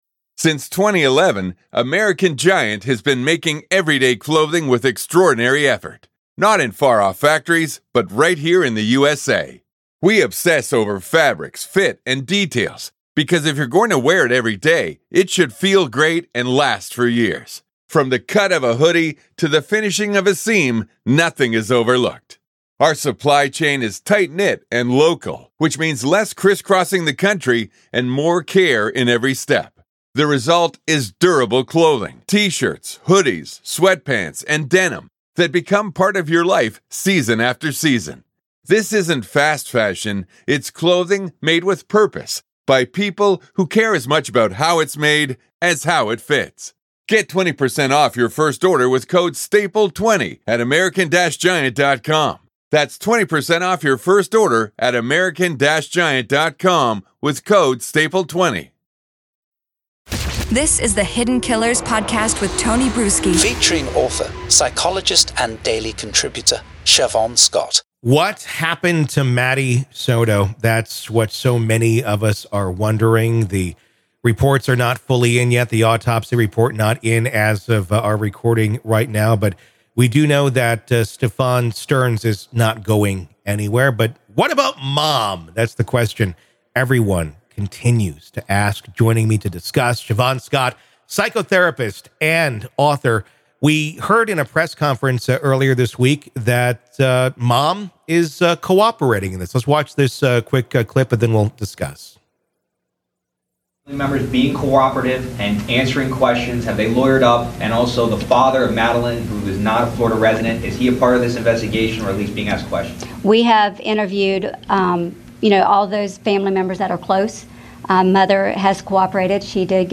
Key points from the conversation include: